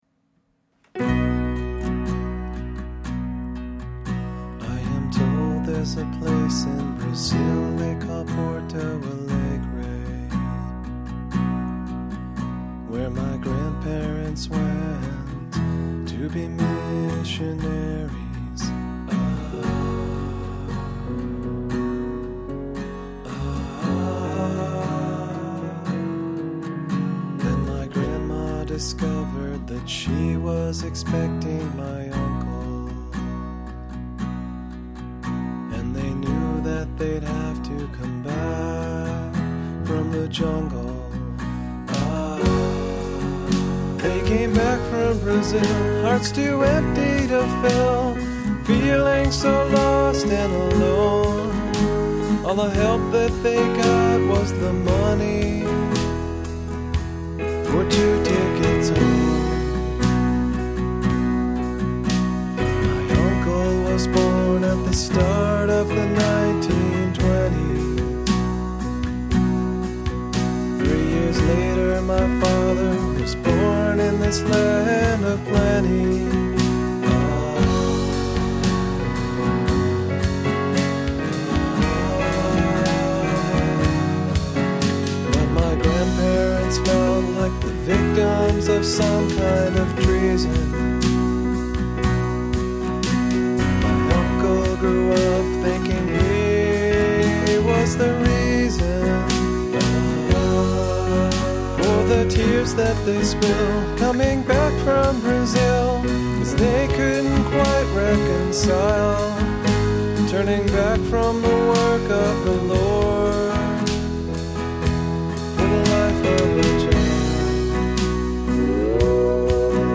Fourteen covers of his highness.